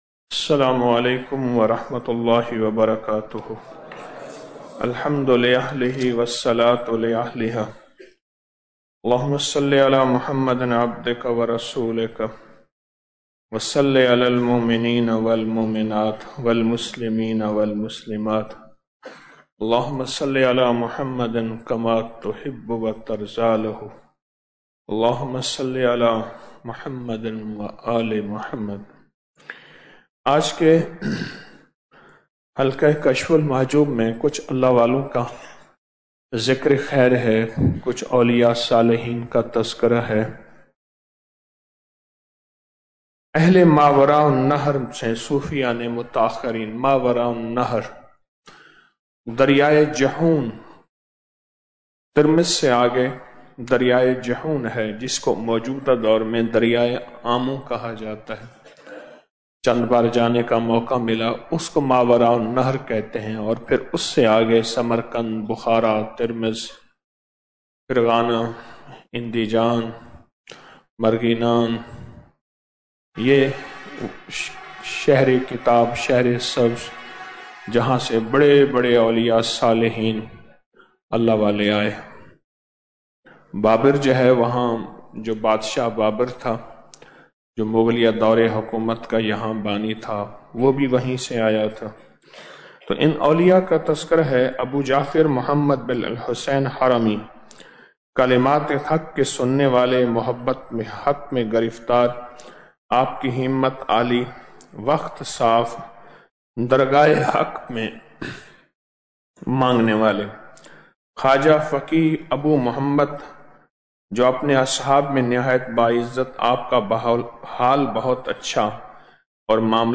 19 رمضان المبارک بعد نماز فجر- 20 مارچ 2025 ء - آڈیو درس 19-Ramzan-2025-Fajar-VOL-38.mp3 اس درس کو ڈاؤنلوڈ کرنے کے لۓ یھاں کلک کریں صدقہ جاریہ کی نیت سے شیئر کریں چند مزید درس سورج گرہن !